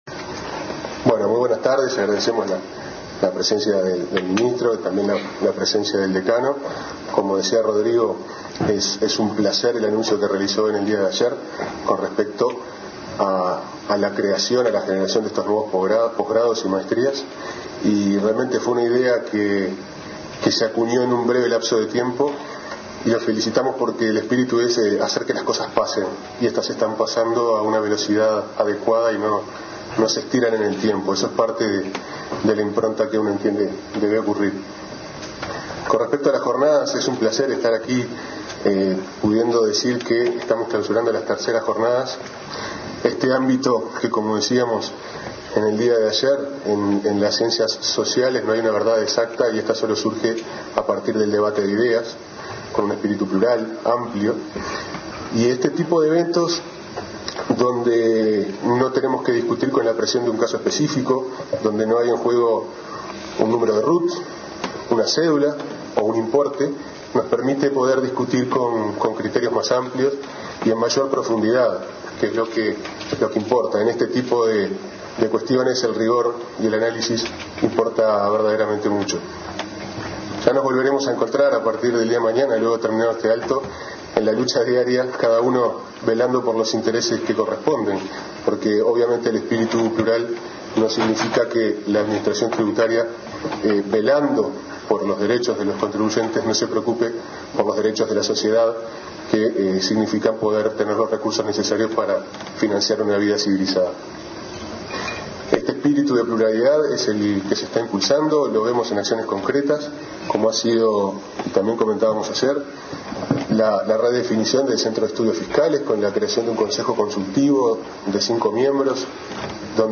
Uno de los art�culos establecer� la posibilidad de cancelar una deuda mediante el pago de un 20% inicial y el resto mediante seis cuotas. Otro habilitar� a la DGI a prescribir obligaciones de oficio, de modo de amparar a un contribuyente sin asesoramiento. El ministro de Econom�a y Finanzas, Fernando Lorenzo, y el director General de Rentas, Pablo Ferreri, participaron de la mesa de cierre de las III Jornadas Tributarias.